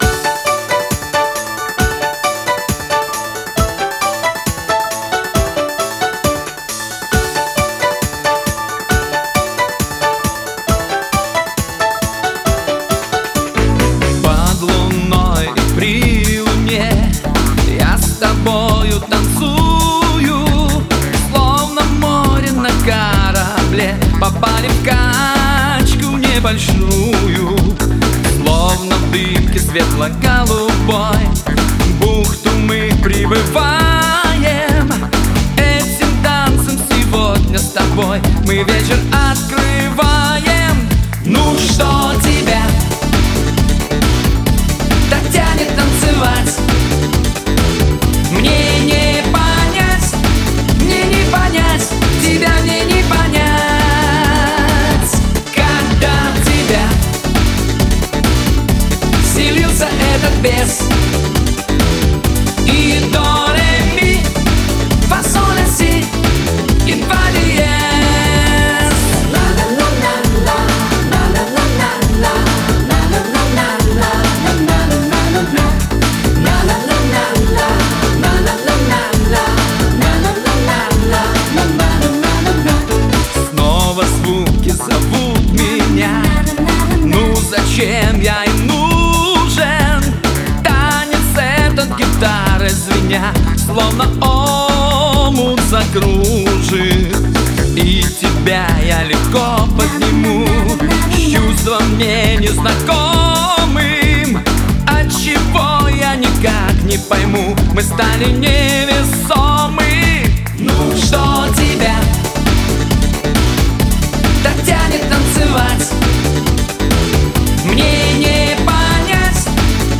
вокал, гитара
бэк вокал
альт-саксофон, клавишные
ударные, перкуссия